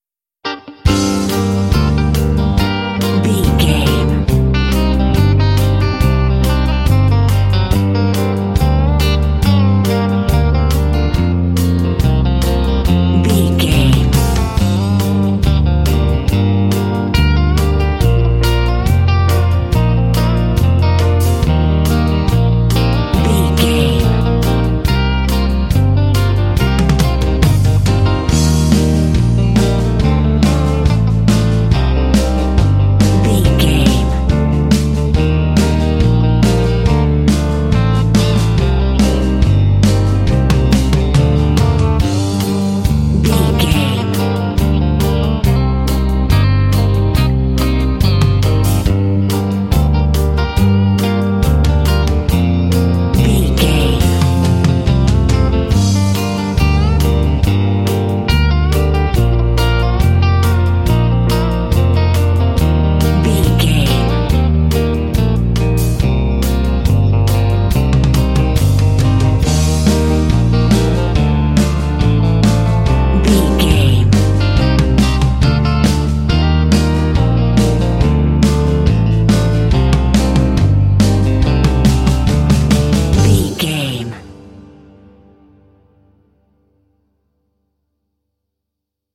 Aeolian/Minor
cool
smooth
calm
groovy
drums
electric guitar
bass guitar
piano
indie
contemporary underscore
country